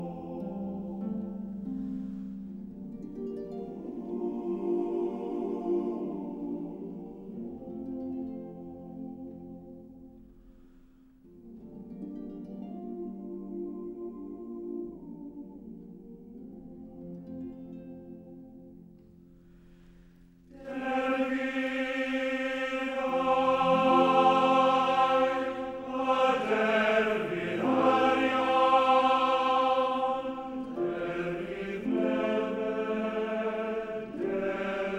Жанр: Соундтрэки / Классика